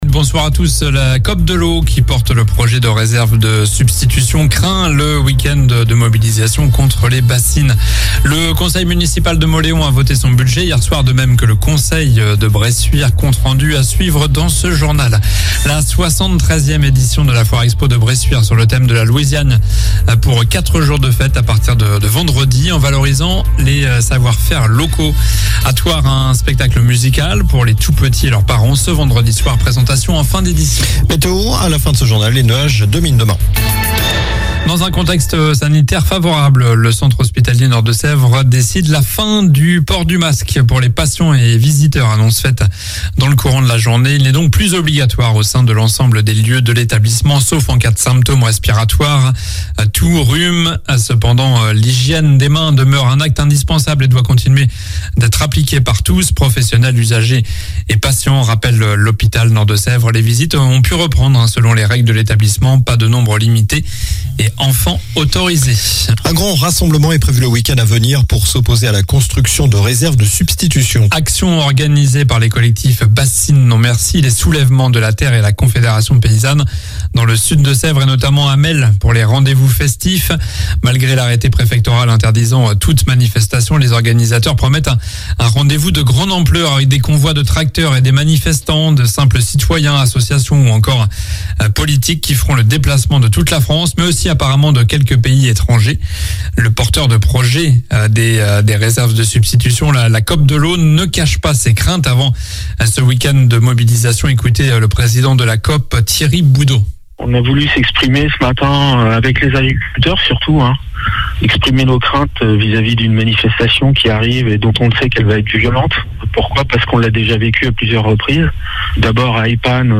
Journal du mardi 21 mars (soir)